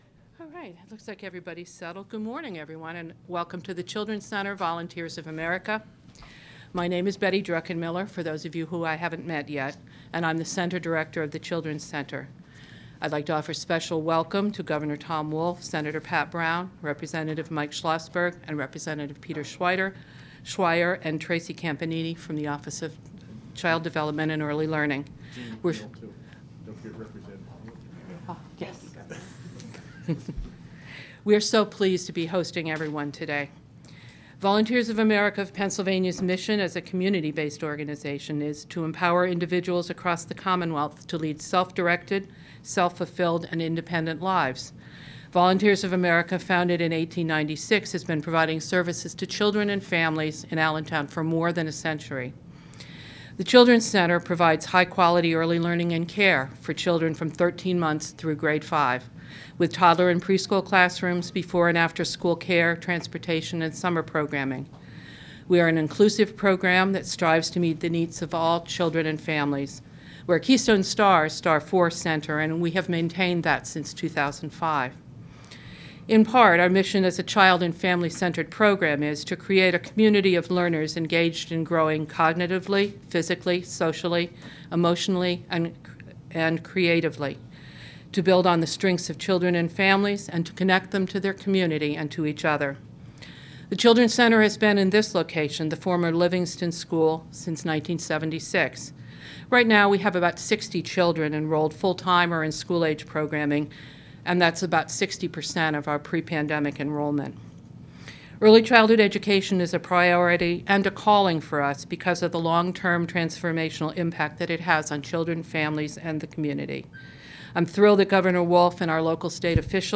Governor Tom Wolf joined childhood advocates and state lawmakers to highlight his accomplishments in increased funding for early childhood education during a visit to the Volunteers of America Children's Center in Allentown.